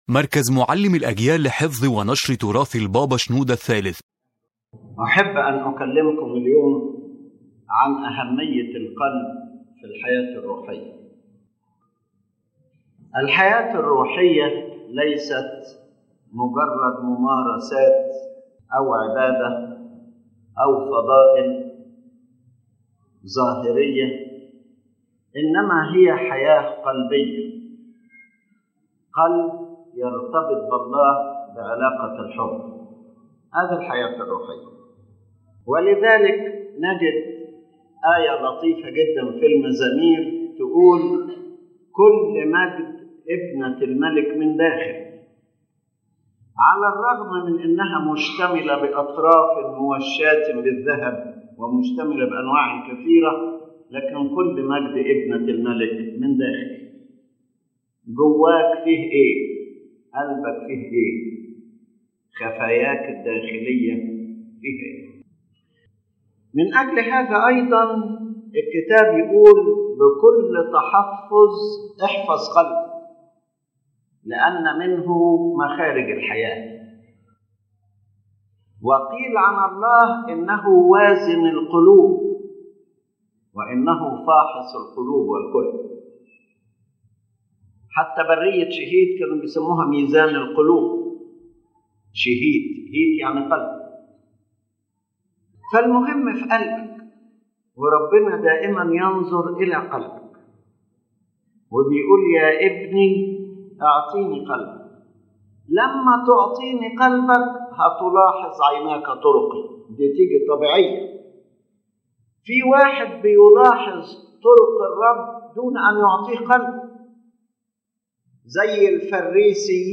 This lecture by Pope Shenouda III explains that the heart is the essence of spiritual life and the foundation of the true relationship with God. Any worship, repentance, or virtue that does not come from the heart remains merely outward and lacks spiritual power.